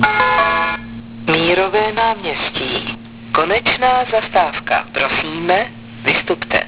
vyhlašování zastávek a mimořádných událostí